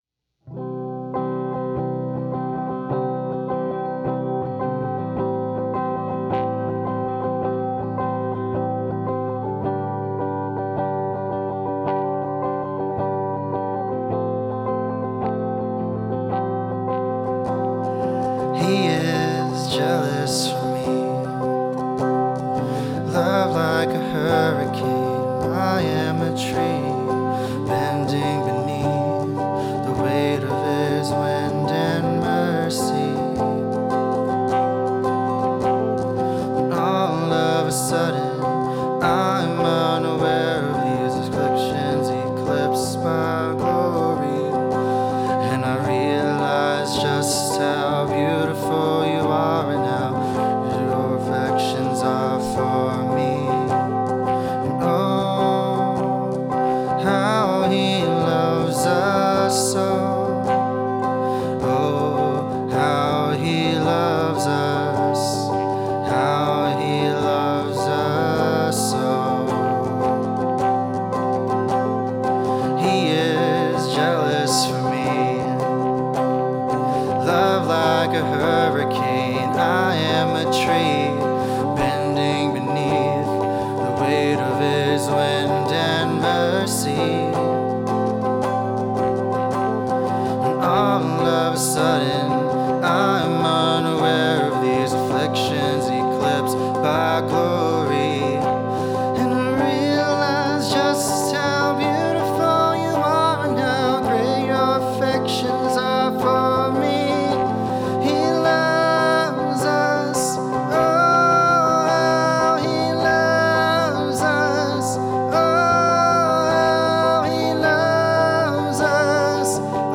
Music form Summer Camp
Song of Praise How He Loves